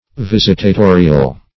Visitatorial - definition of Visitatorial - synonyms, pronunciation, spelling from Free Dictionary
Visitatorial \Vis`it*a*to"ri*al\, a. [Cf. LL. visitator a bishop